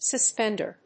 /səˈspɛndə(英国英語), sʌˈspɛndɝ(米国英語)/